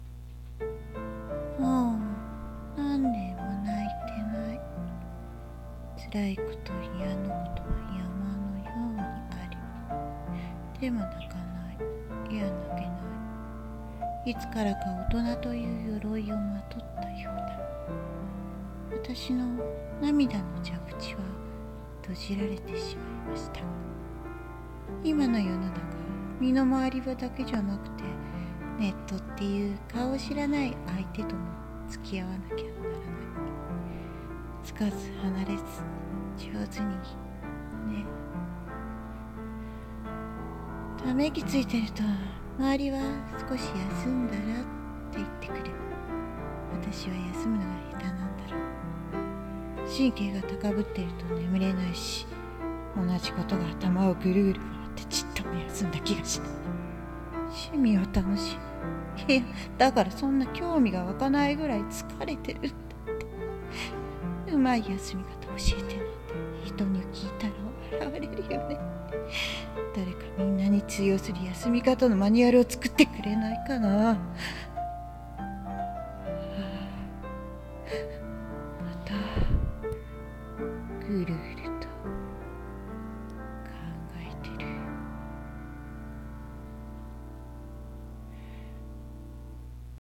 】1人声劇台本「休み方マニュアル」 【演者】